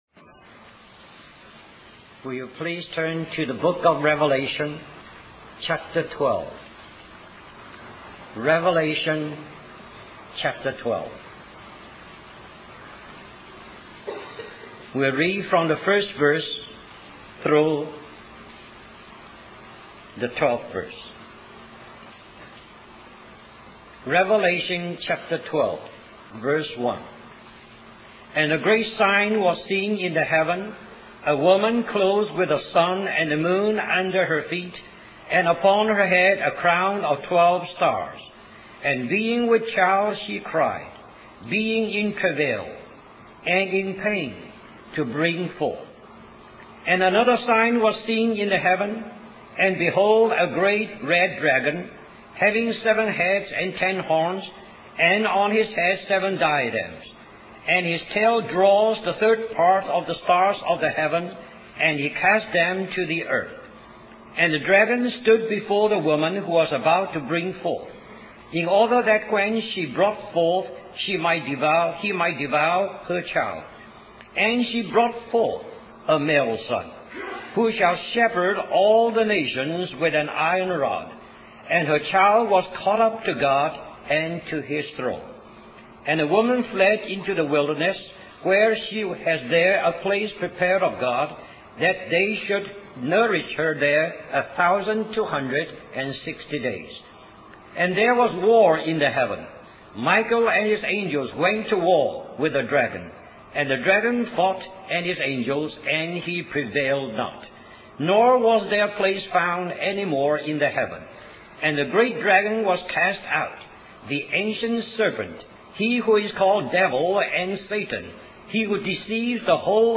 1988 Christian Family Conference Stream or download mp3 Summary During June